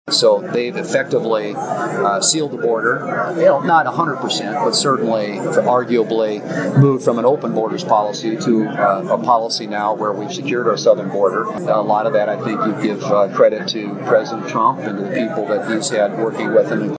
WATERTOWN, S.D.(KXLG)- U.S. Senator John Thune visited Watertown Thursday to address the local Rotary Club during their meeting held at the Elks Lodge.